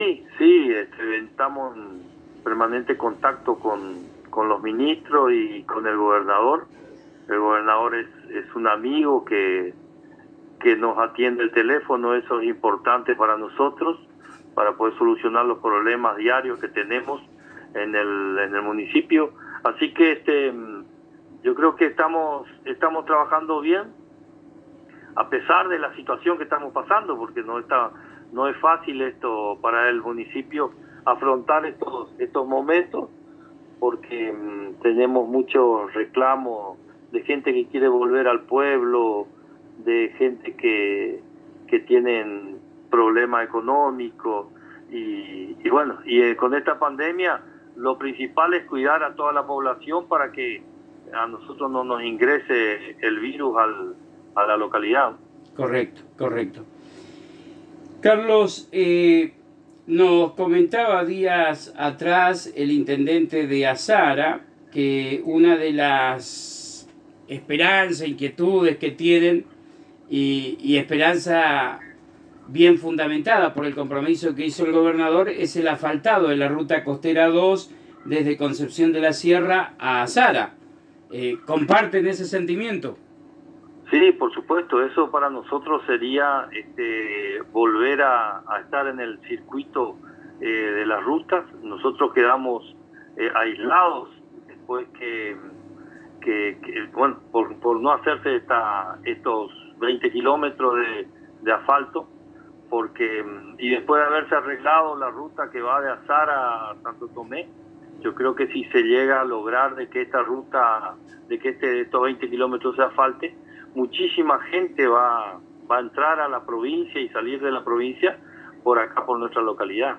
El intendente de Concepción de la Sierra, Carlos Pernigotti, en entrevista exclusiva con la ANG y el programa «Lo Mejor de la Ciudad» resaltó el acompañamiento y apoyo del Gobernador Oscar Herrera Ahuad y sus ministros. También remarcó el trabajo que se viene realizando en su localidad en la lucha contra el coronavirus. Asimismo manifestó que se siguen trabajos de empedrados, cordón cunetas, y todas las acciones de acompañamiento hacia los que más necesitan.